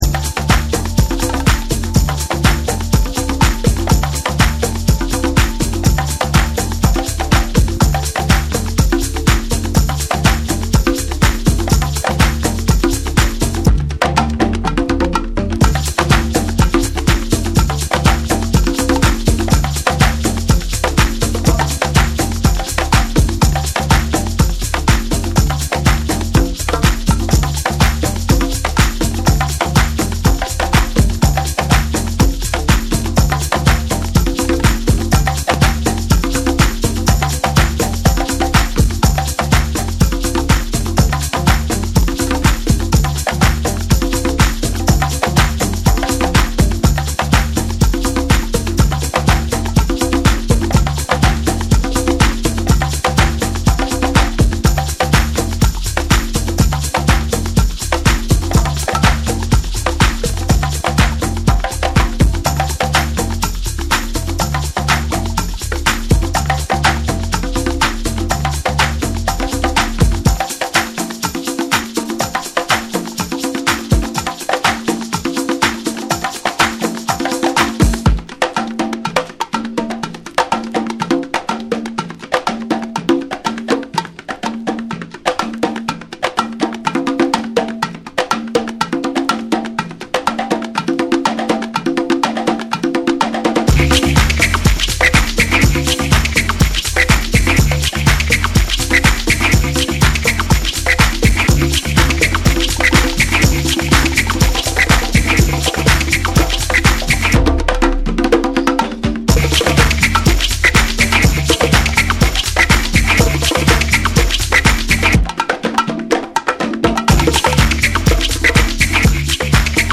TECHNO & HOUSE / ORGANIC GROOVE